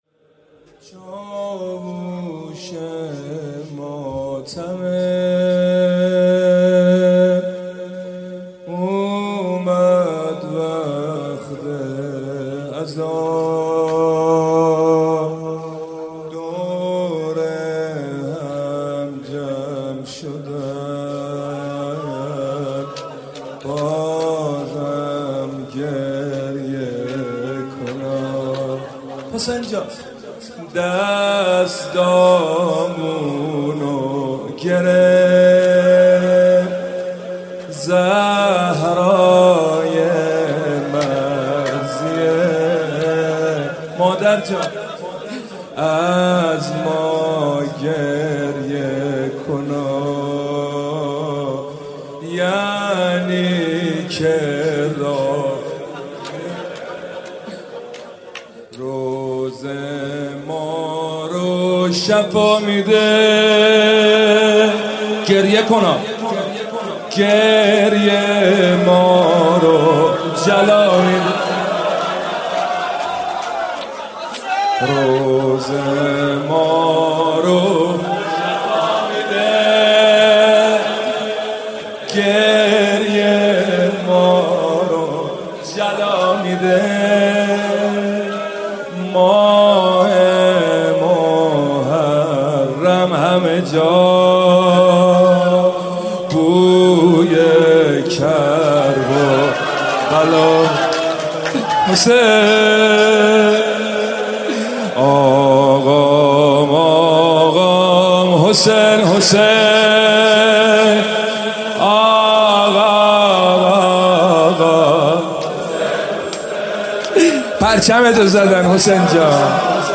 اولین شب از مـــراســم عـــزاداری دهــه اول مـــحــرم الـحــرام در هیئت روضه العباس(ع
روضه
شور